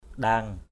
/ɗa:ŋ/